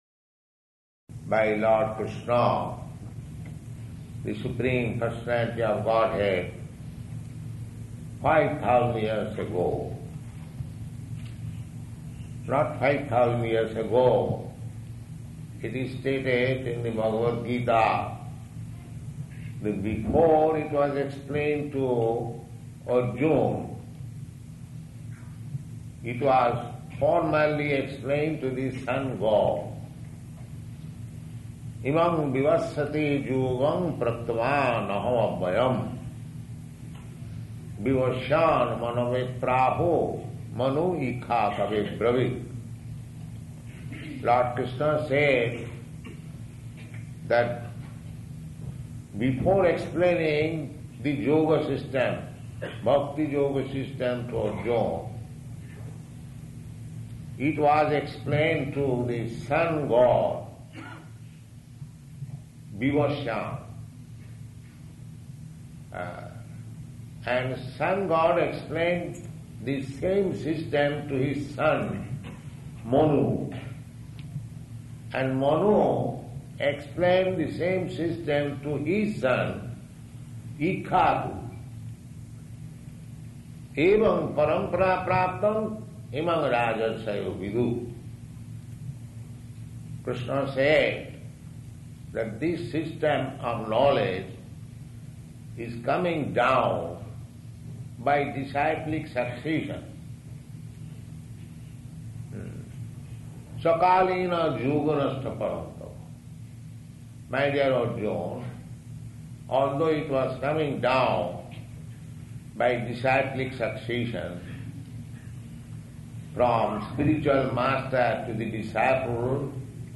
Bhagavad-gītā 4.1 --:-- --:-- Type: Bhagavad-gita Dated: November 10th 1971 Location: Delhi Audio file: 711110BG-DELHI.mp3 Prabhupāda: ...by Lord Kṛṣṇa, the Supreme Personality of Godhead, five thousand years ago...